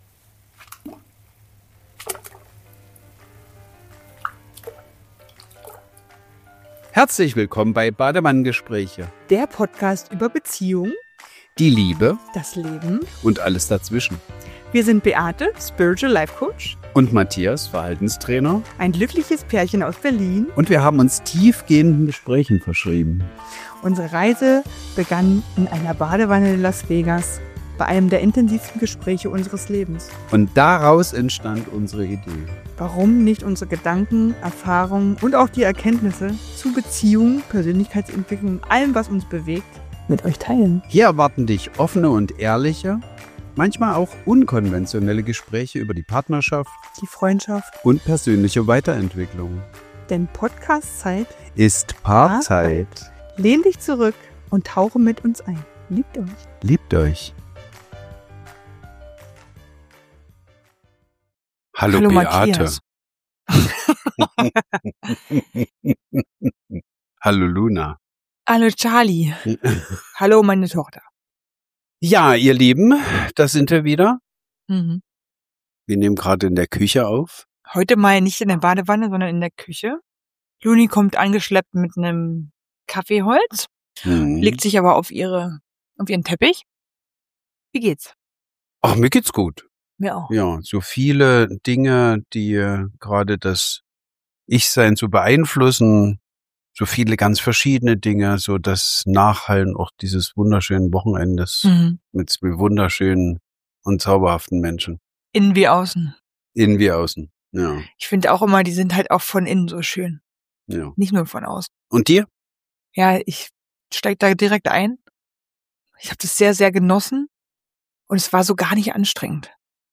Sie ist ehrlich, manchmal unbequem und zeigt auch, dass wir als Paar nicht immer die gleiche Sicht haben – und dass genau das Teil einer echten Beziehung ist.